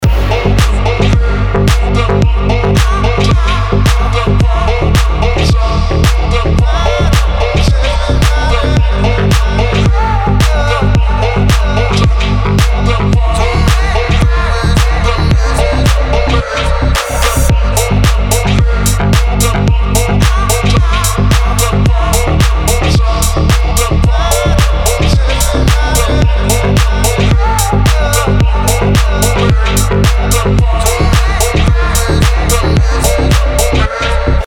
• Качество: 320, Stereo
громкие
EDM
Bass
house